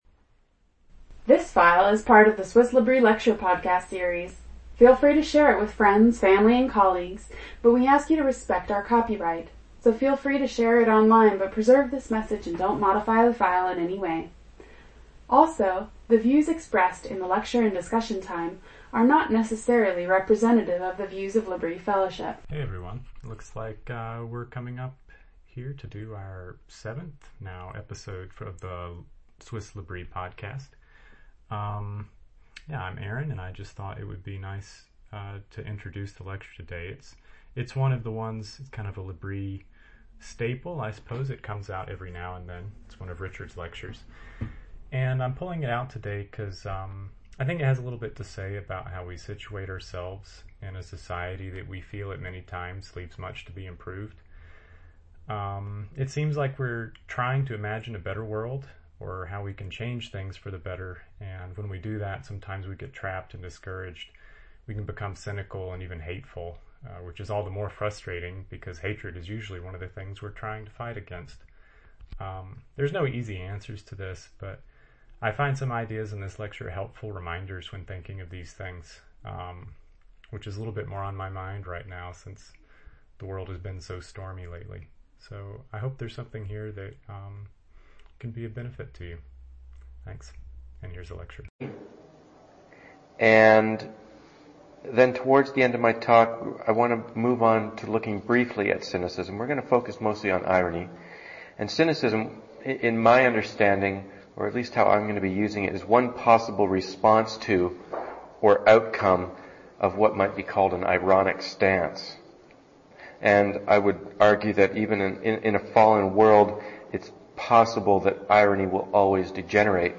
There are no easy answers to this, but the ideas in this lecture can be helpful reminders when thinking of these things.